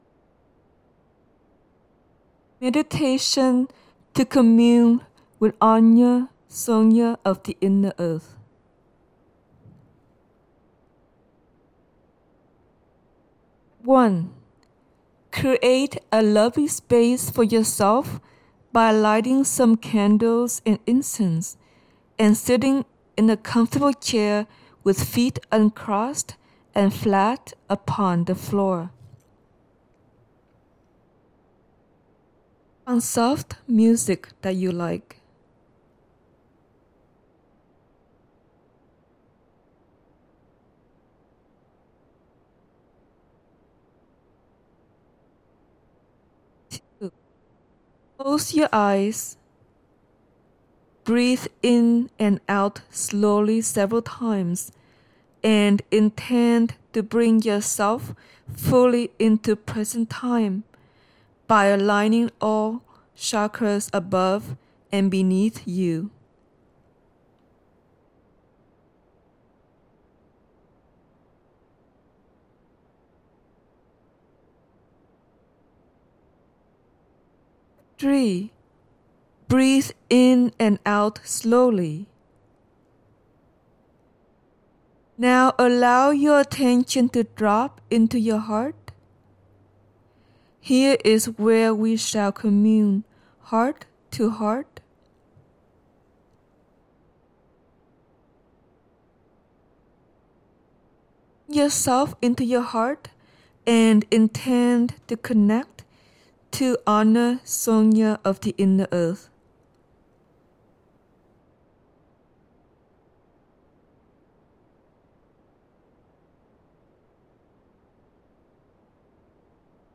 Meditation-to-Commune-with-Anasonya-of-the-Inner-Earth.mp3